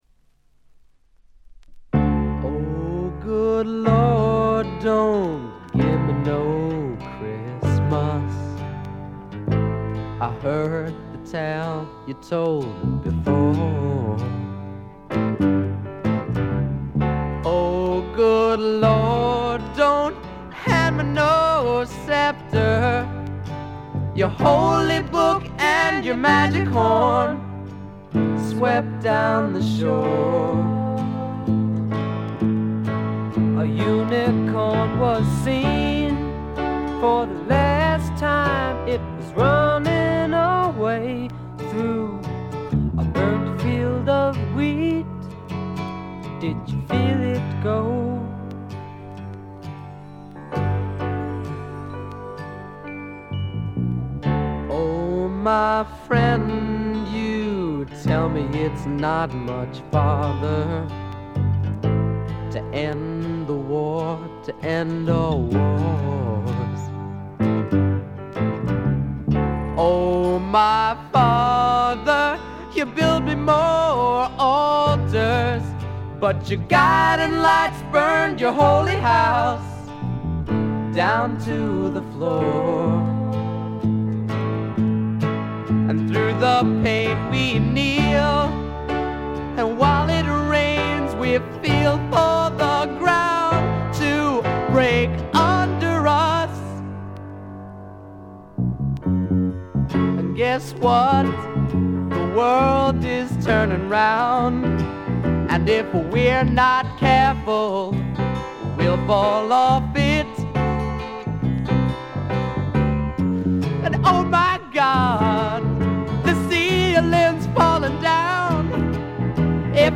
シンガーソングライター・デュオ
きらきらときらめくドリーミーフォーク的な感覚も素晴らしい。
試聴曲は現品からの取り込み音源です。